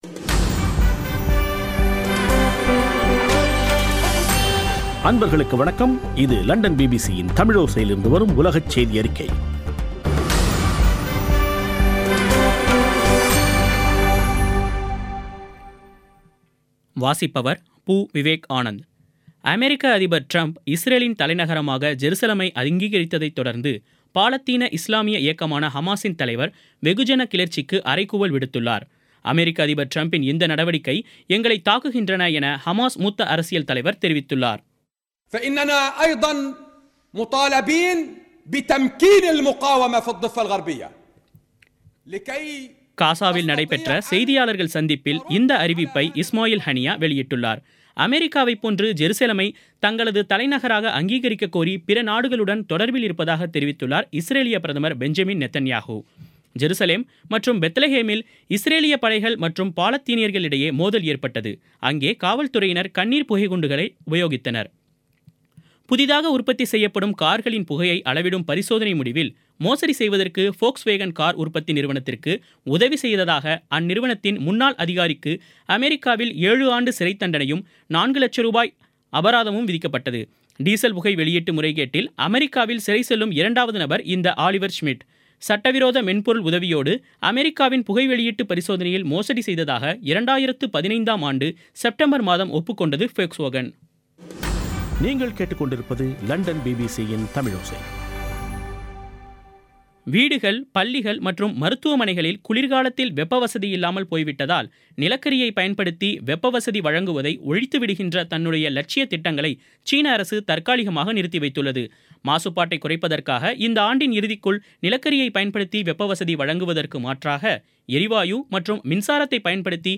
பிபிசி தமிழோசை செய்தியறிக்கை (07/12/2017)